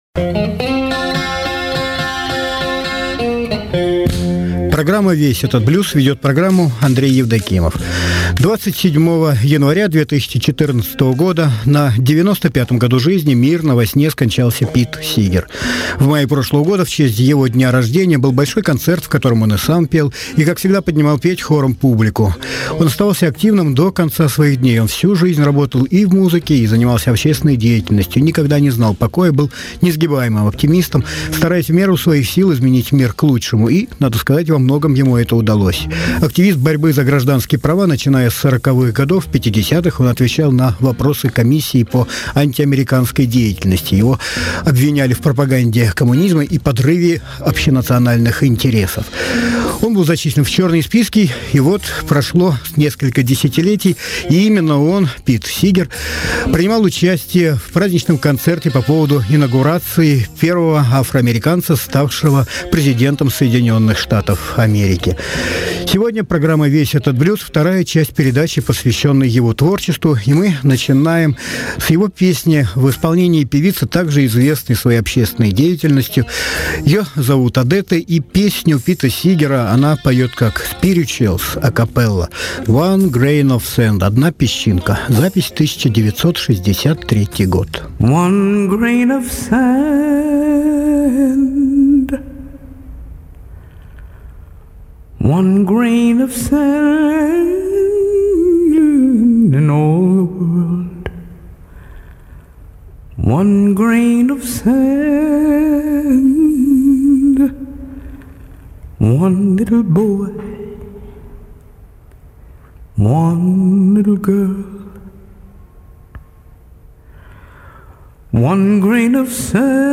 Сегодня мы поговорим о его творческом пути и послушаем песни в его исполнении, а также его песни в исполнении других музыкантов. 1.